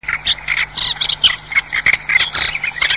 dzierzba białoczelna - Lanius nubicus (dotychczas w Polsce nie obserwowana)mp324 kb